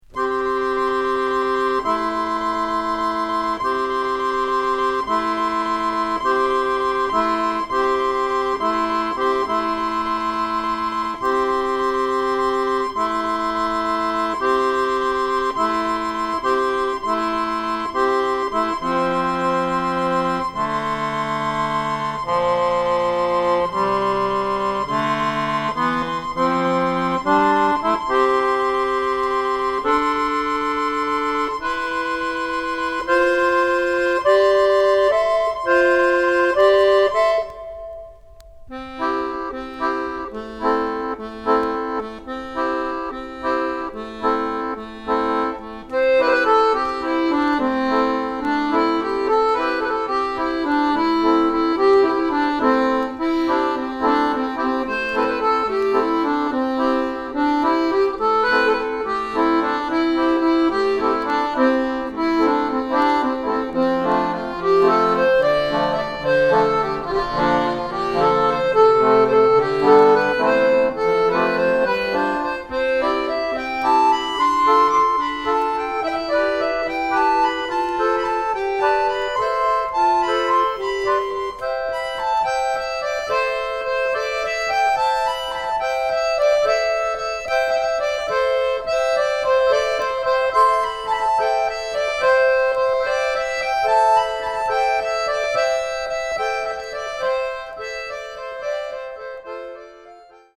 キーワード：ドローン　即興　フォーク